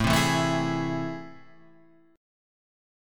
AmM7 chord